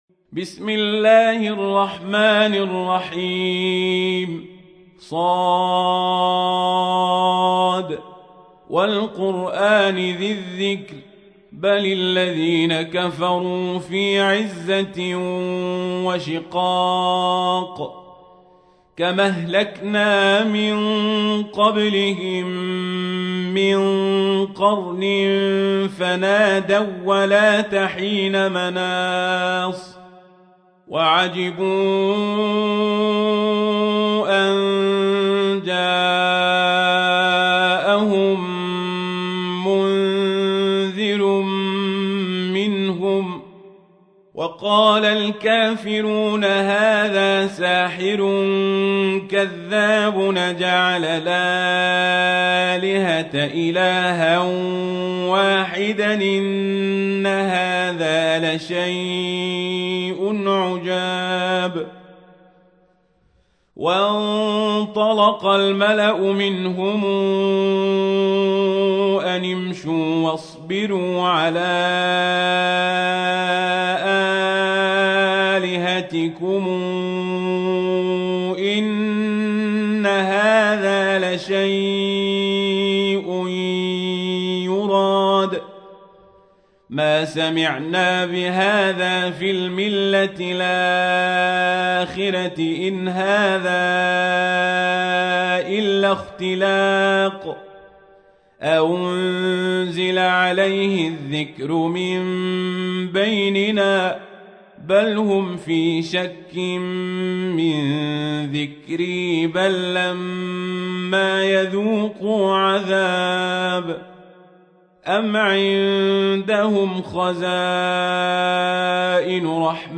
تحميل : 38. سورة ص / القارئ القزابري / القرآن الكريم / موقع يا حسين